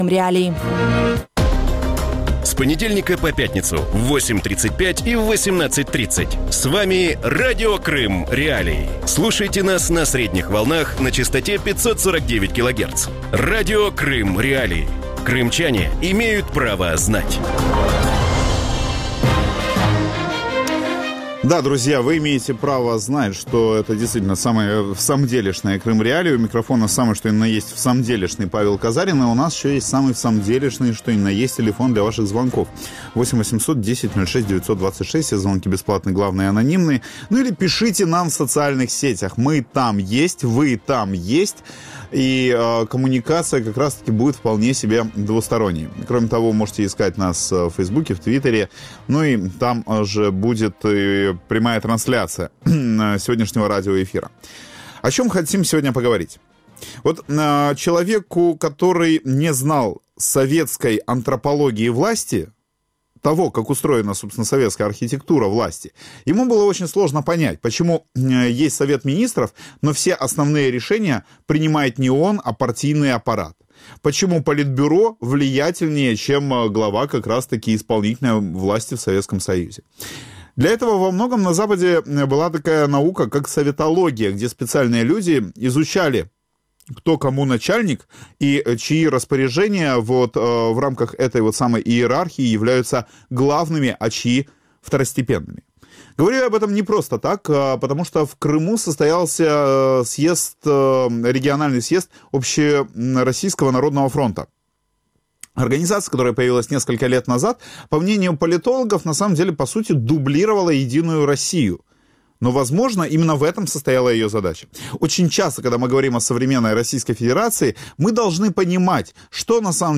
У вечірньому ефірі Радіо Крим.Реалії обговорюють участь російського президента Володимира Путіна у форумі Загальноросійського народного фронту (ЗНФ) в анексованому Криму. Чим займається Загальноросійський народний фронт на півострові, як пройшов форум і які обіцянки дав Володимир Путін кримчанам?